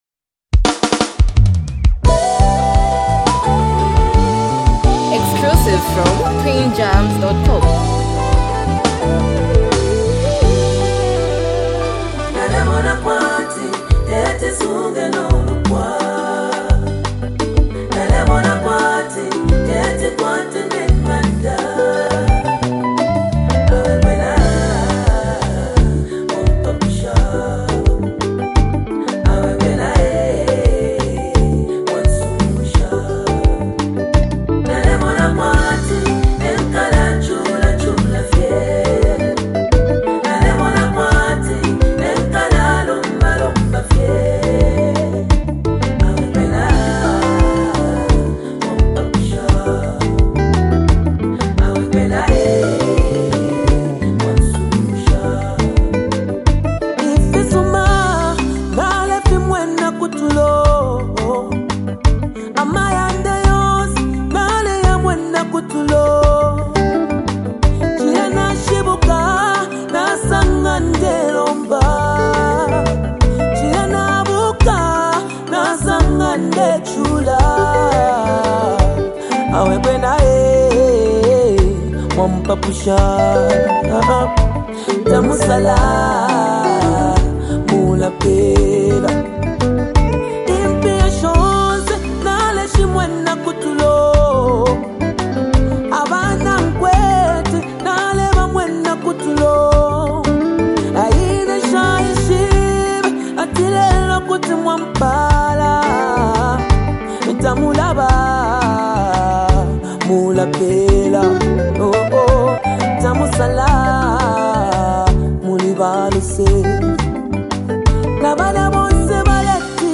emotional and reflective song
Through honest lyrics and a soulful delivery